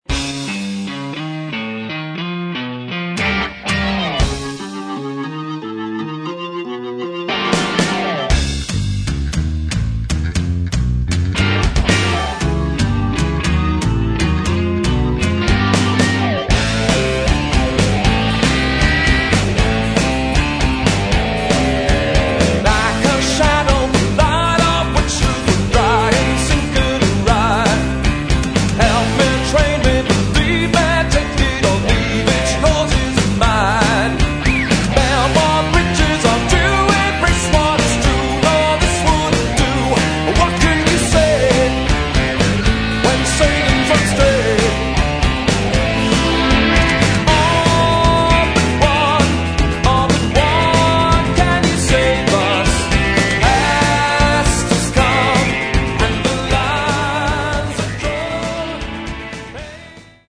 Metal
Очень нетрадиционный Progressive Metal.